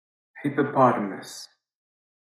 Sound Buttons: Sound Buttons View : Hippopotamus